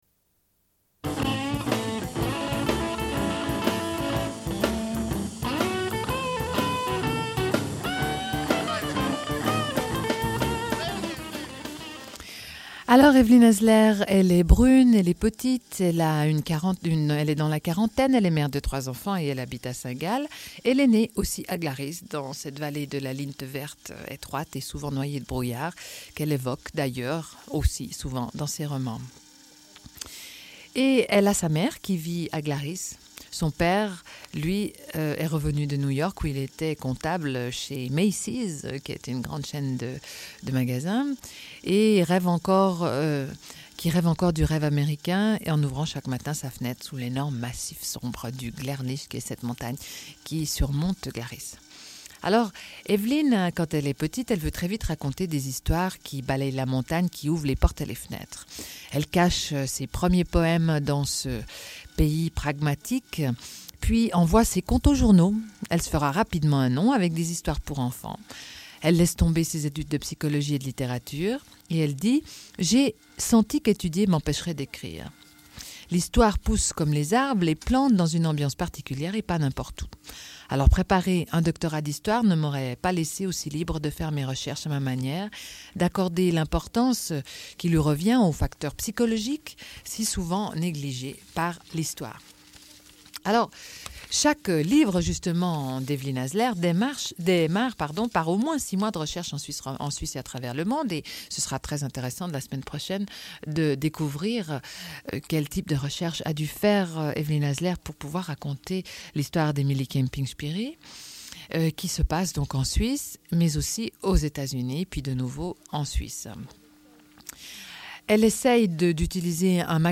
Une cassette audio, face B28:43
Lecture de deux portraits de femmes.